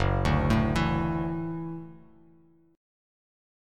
F#m7#5 chord